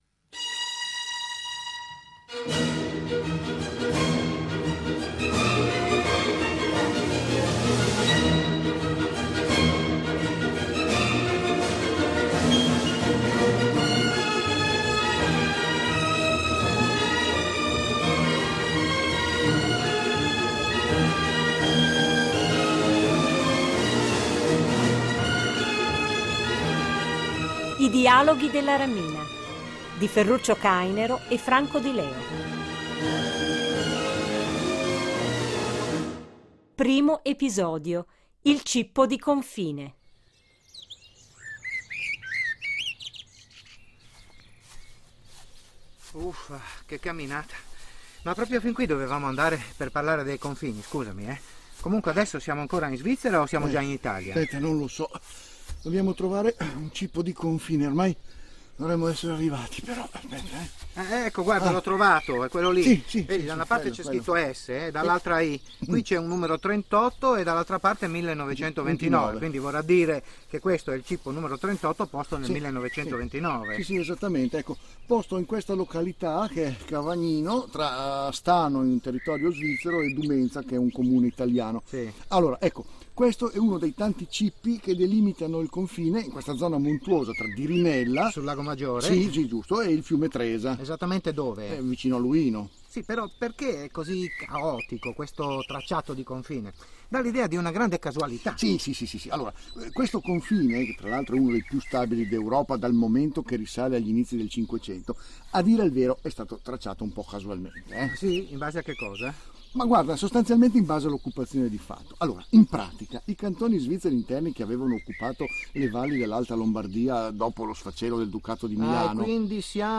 Drama RSI